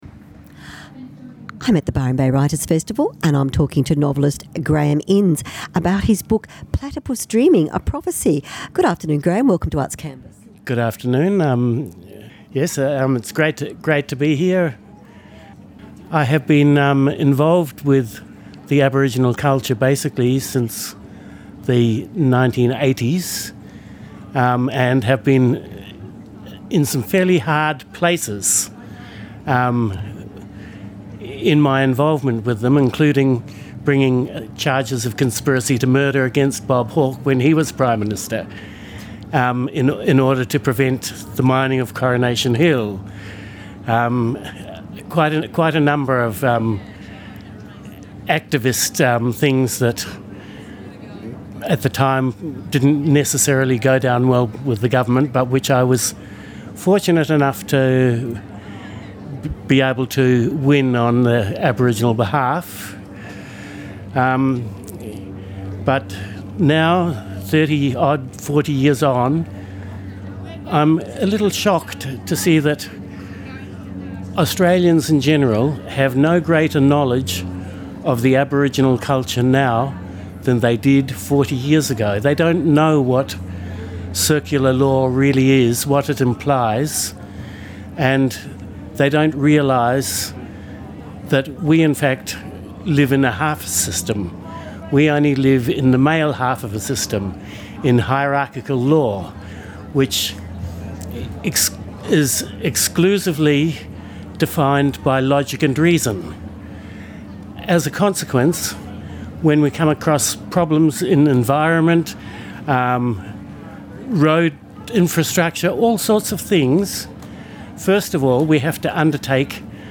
Books and Authors - live interviews, Indigenous issues
Recorded at Byron  Writers Festival 2015